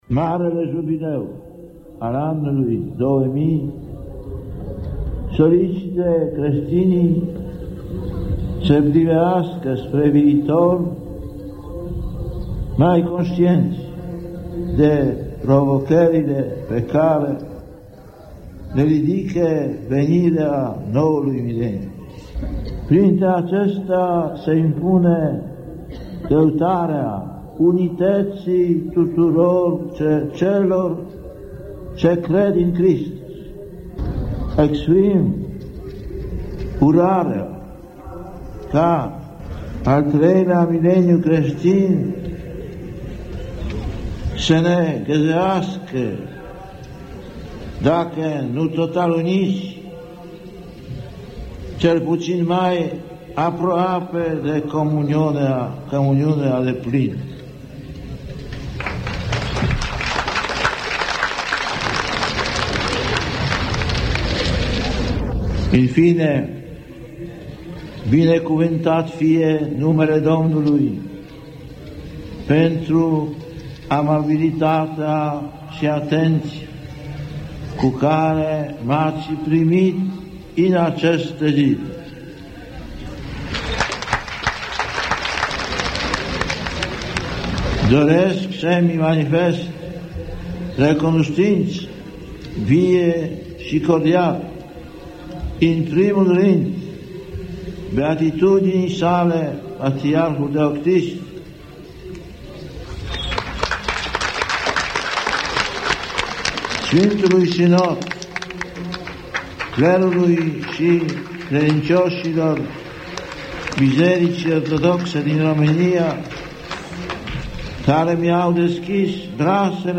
înregistrări-document (1999) cu Ioan Paul al II-lea